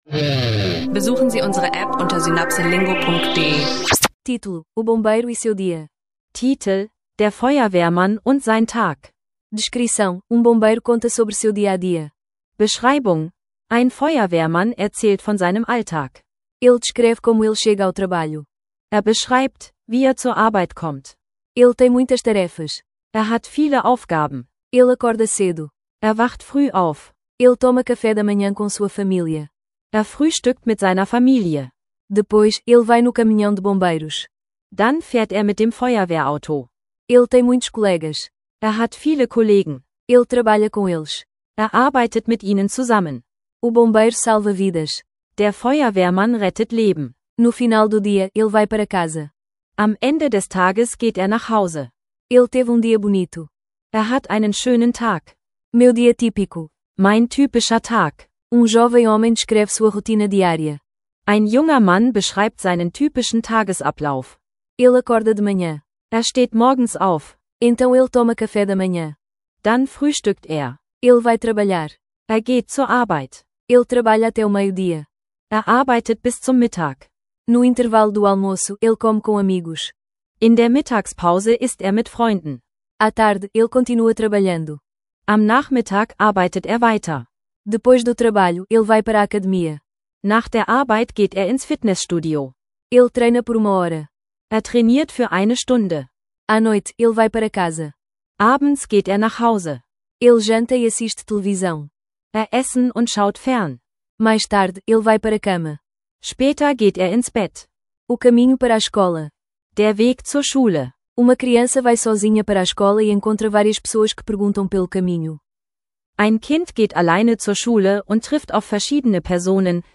Feuerwehrmann dich mit durch seinen Alltag und lehrt dabei